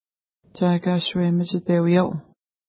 Pronunciation: tʃa:ka:ʃwe-mətʃitewija:w